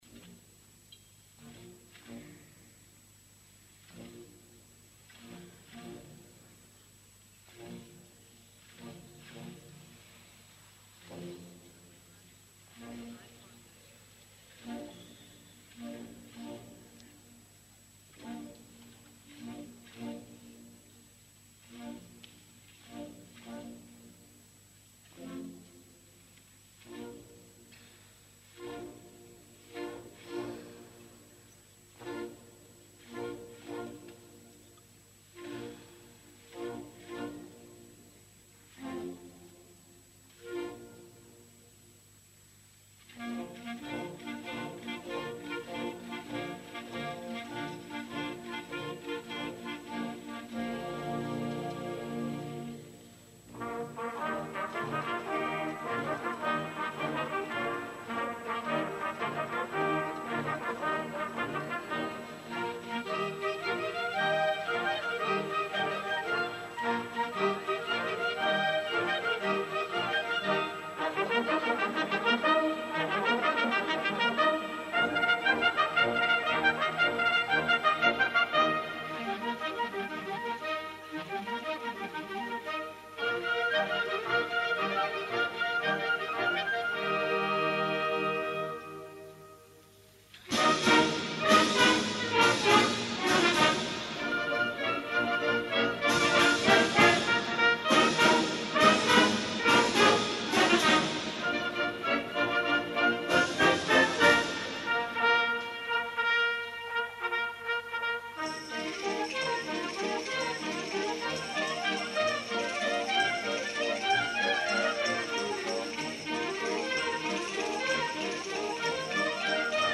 Concert sa fira 1988. Esglesia parroquial de Porreres Nostra Senyora de la Consolació.